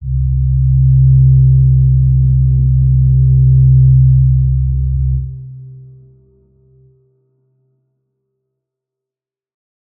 G_Crystal-C3-f.wav